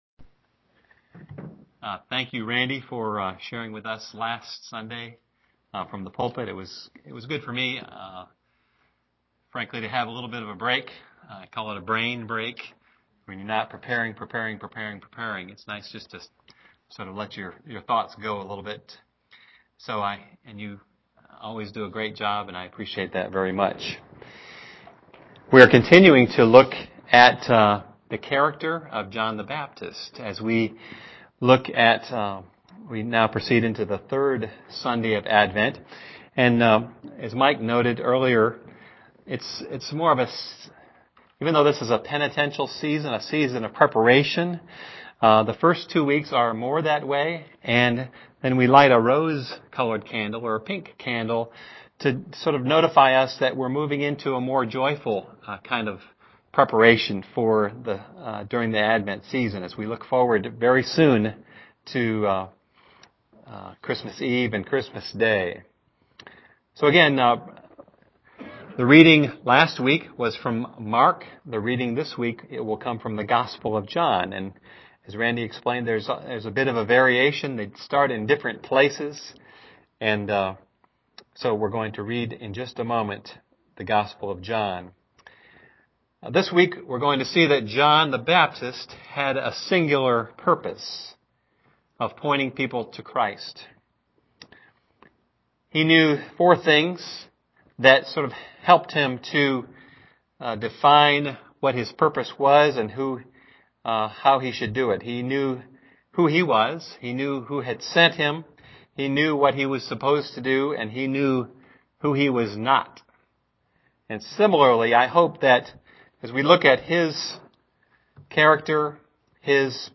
Home › Sermons › Sermons by Year › 2011 › Pointing People to Jesus (John 1:6-8, 19-28)